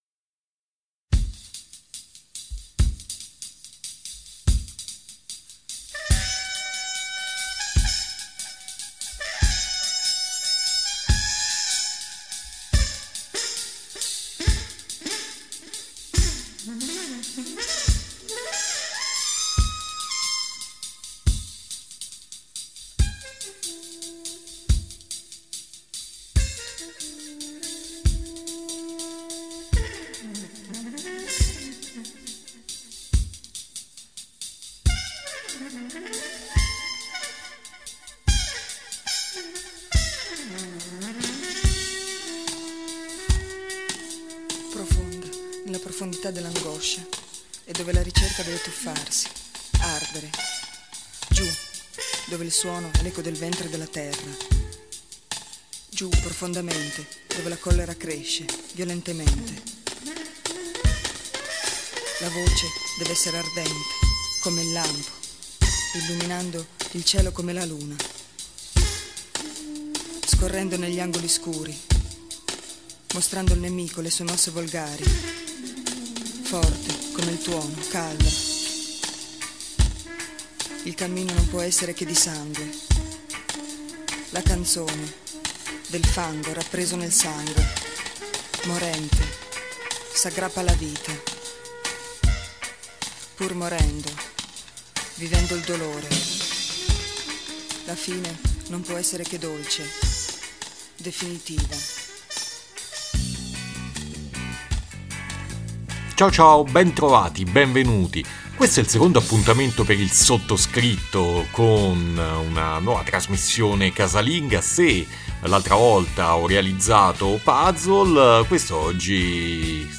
Trasmissione di musica underground italiana